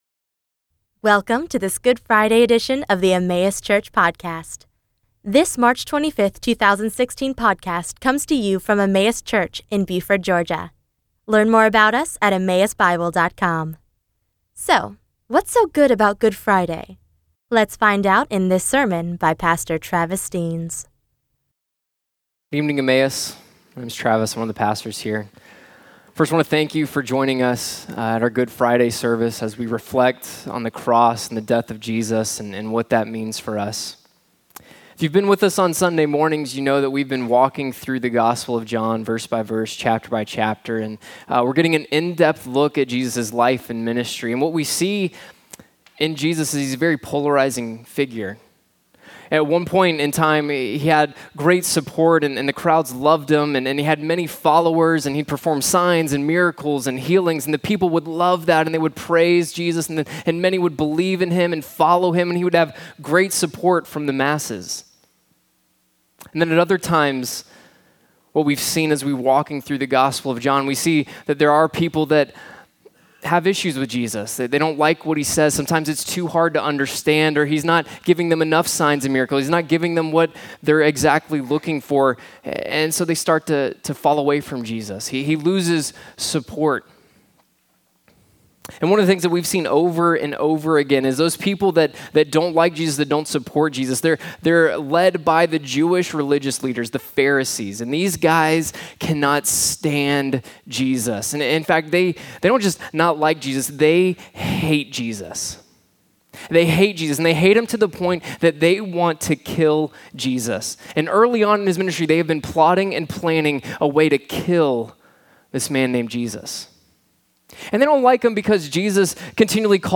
Good Friday Service